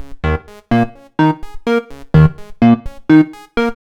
Echo Song_126_Db.wav